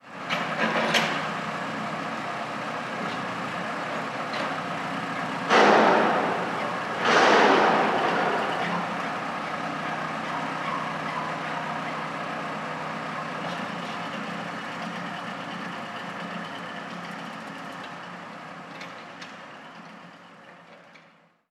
Ambiente obra de construcción
Sonidos: Industria Edificación y obra civil